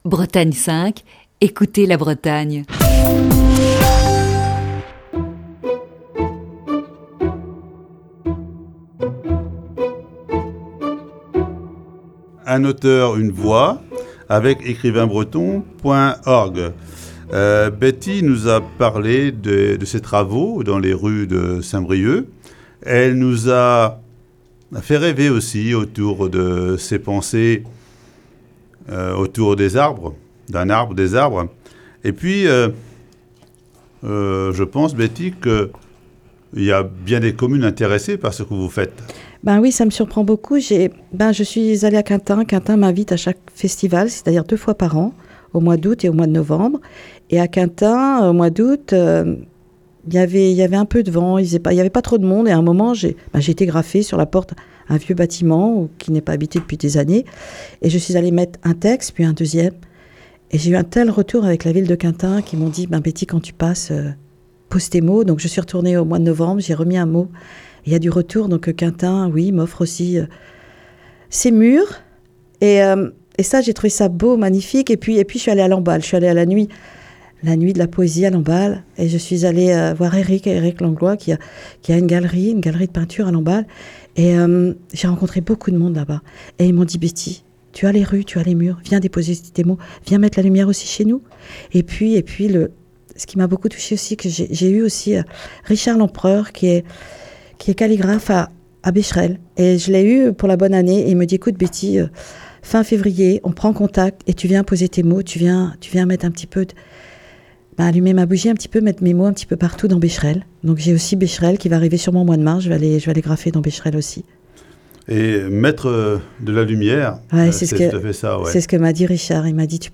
Ce jeudi, voici la quatrième partie de cet entretien.